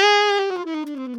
Index of /90_sSampleCDs/Giga Samples Collection/Sax/ALTO DOUBLE
ALTOLNGFLG#3.wav